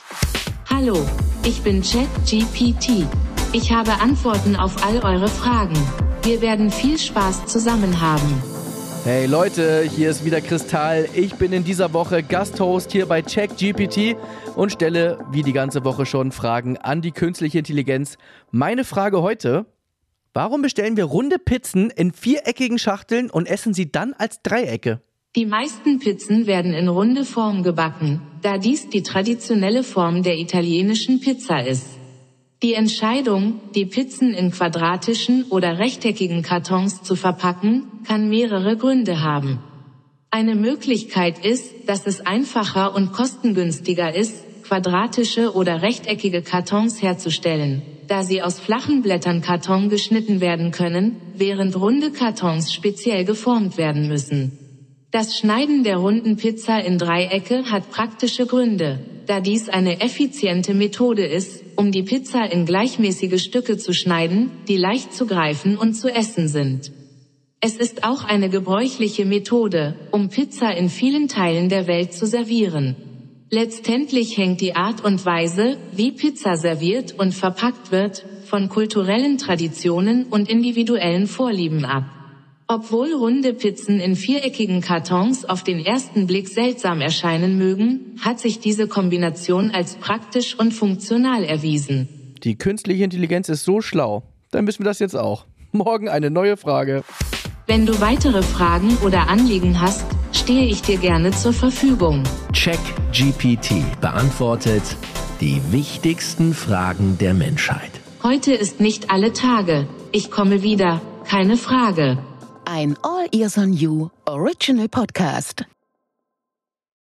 Chris Tall & KI
im Podcast stellt er die Fragen an die Künstliche Intelligenz.